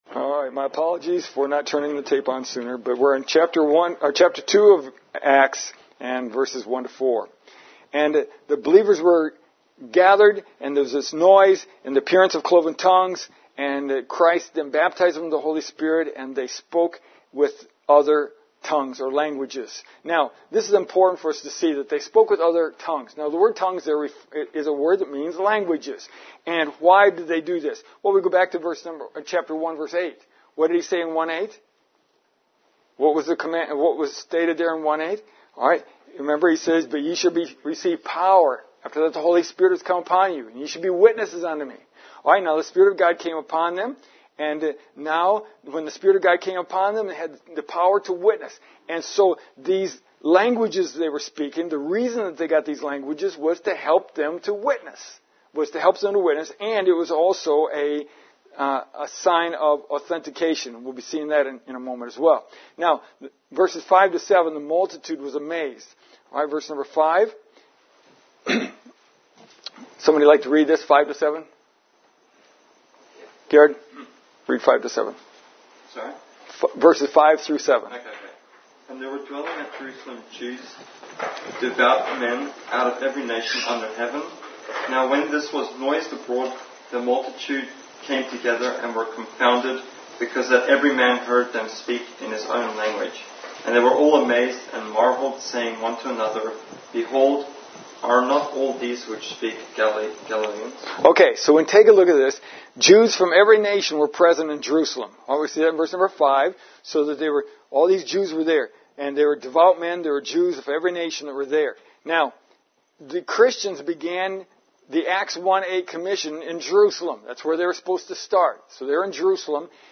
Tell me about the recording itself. The audio has several interactions with the congregation which are somewhat difficult to hear.